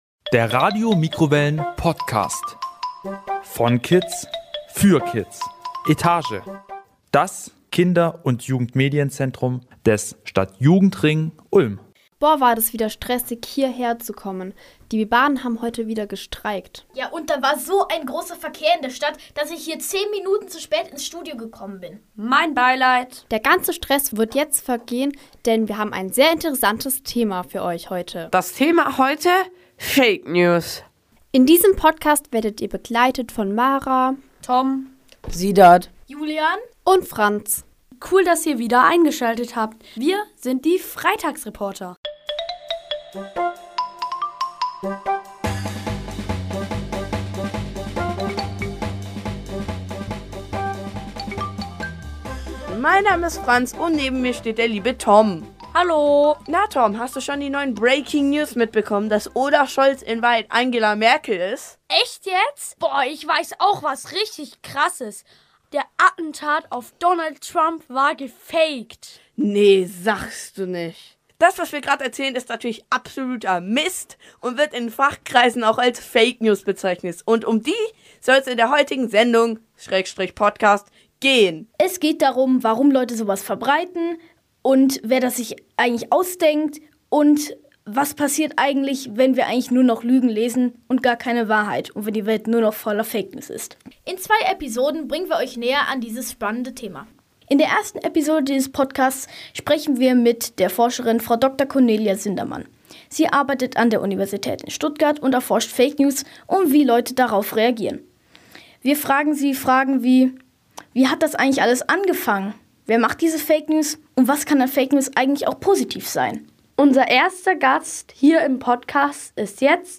Die Freitagsreporter im Gespräch.